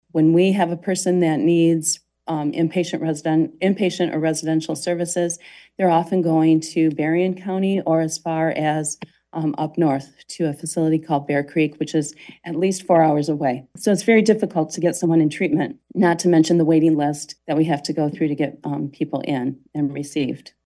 COLDWATER, MI (WTVB) – The Branch County Board of Commissioners heard a pair of requests for the 18-year allocation of opioid settlement funds during last Thursday’s work session.